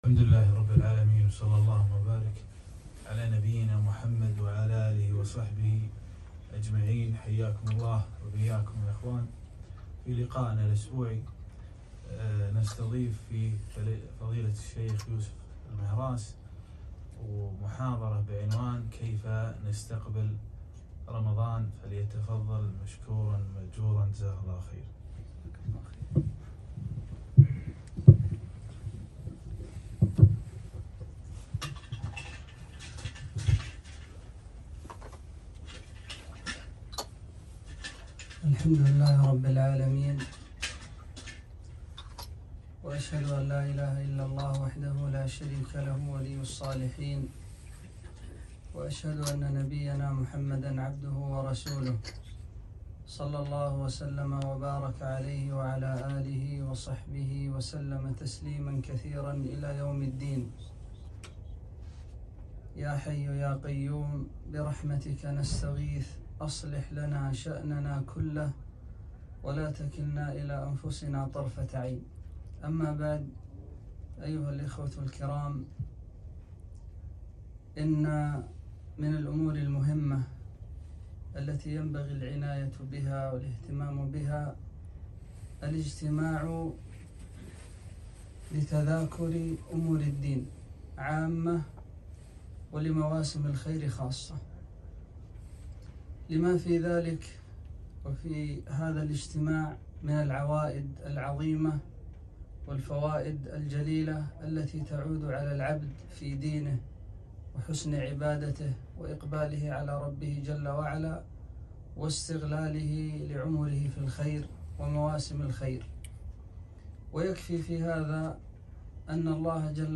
محاضرة - كيف نستقبل رمضان ؟